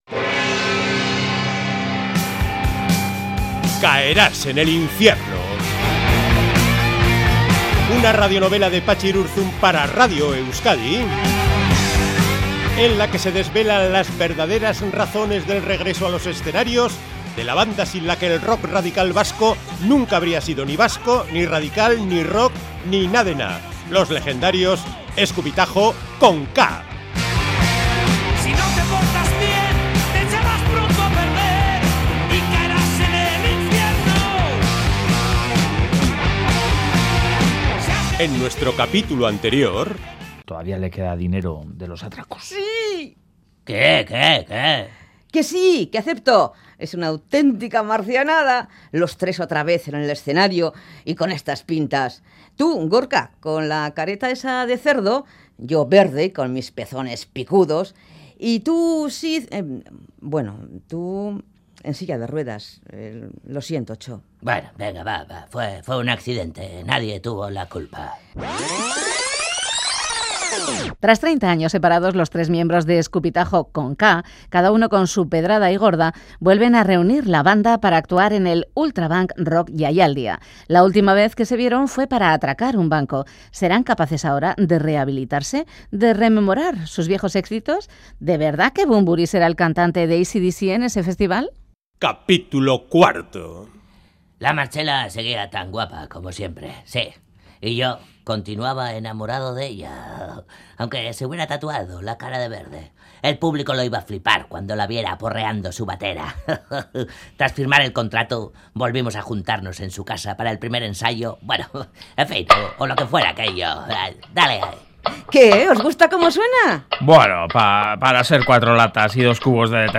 Musica| Radio novela| Patxi Irurzun| Eskupitajo con K| RADIO EUSKADI